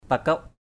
/pa-kəʊʔ/ (đg. t.) cấm, ngăn trở; kiêng cử = défendre. to forbid, abstain; taboo.